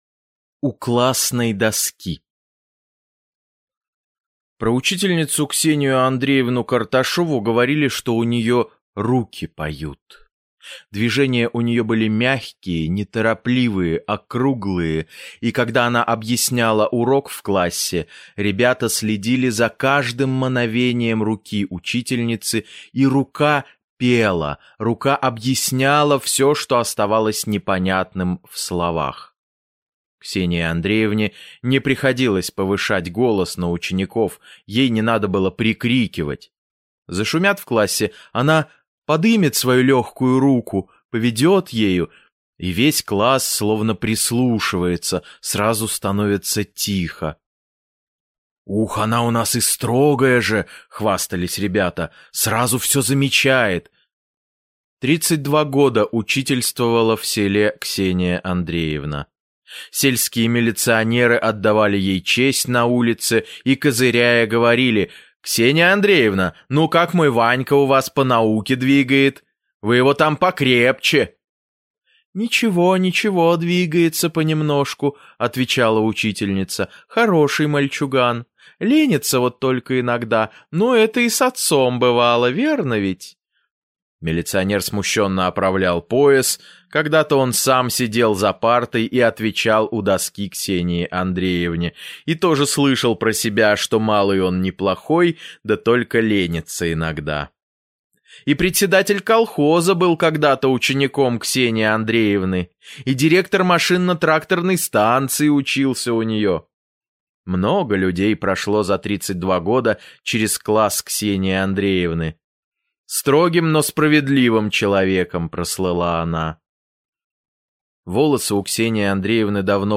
У классной доски - аудио рассказ Кассиля - слушать онлайн